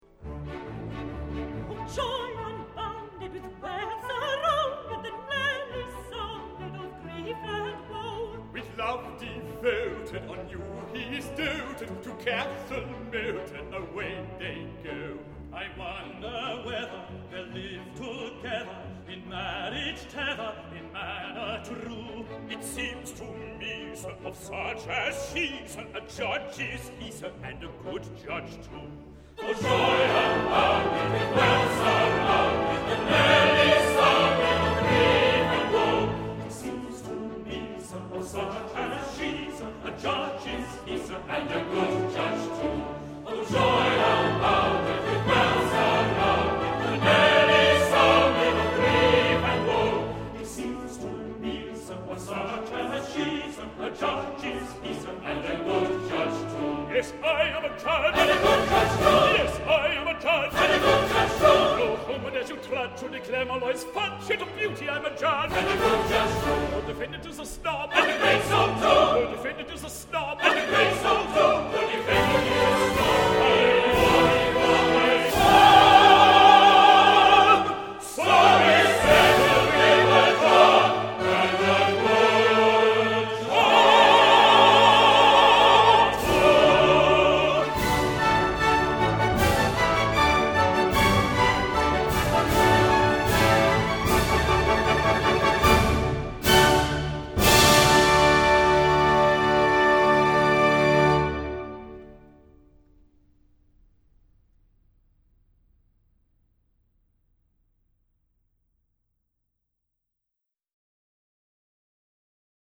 Cette matinée lyrique dominicale est consacrée à une opéra-comique anglais –une cantate dramatique en un acte– extrêmement court –une trentaine de minutes à peine– du duo Gilbert –librettiste– et Sullivan –compositeur-.